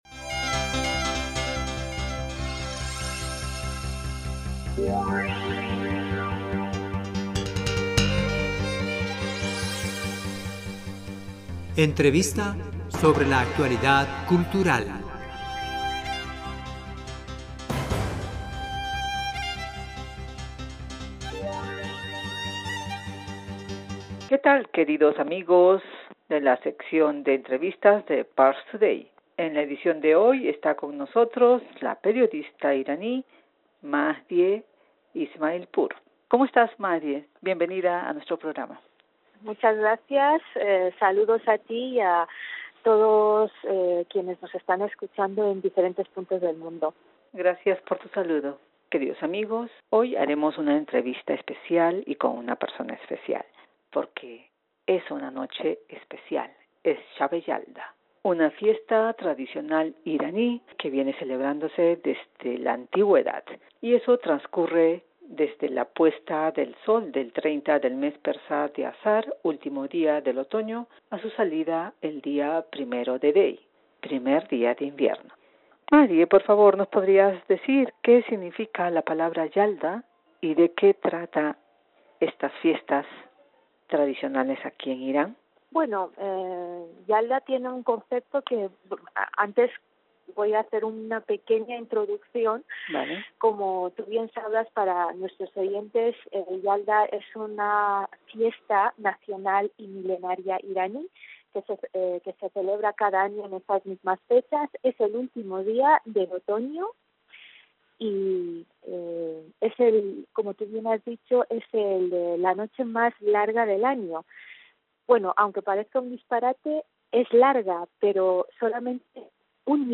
Queridos amigos, hoy haremos una entrevista especial y con una persona especial porque es una noche especial.